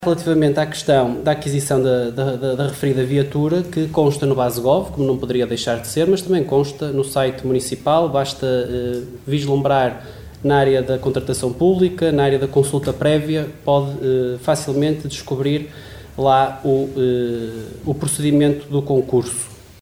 Excertos da reunião de Câmara realizada ontem à tarde nos Paços do Concelho.